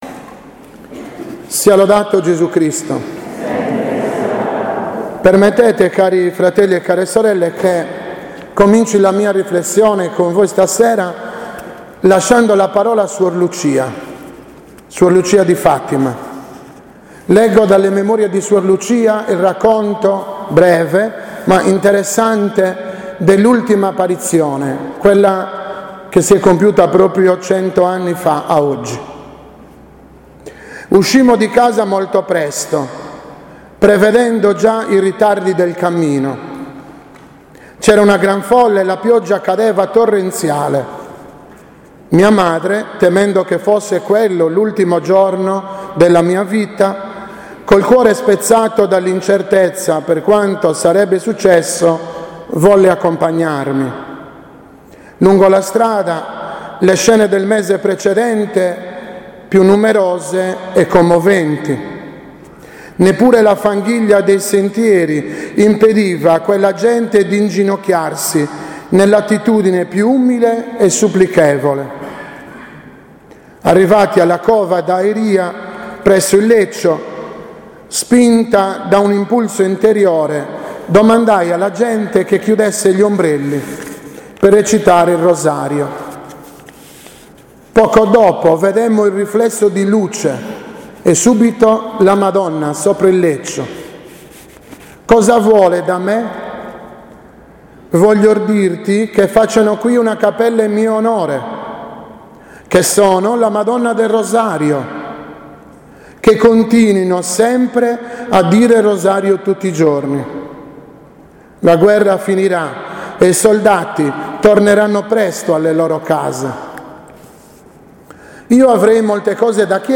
Omelia
alla S. Messa Solenne celebrata in occasione del 100 anniv. dell’ultima apparizione a Fatima.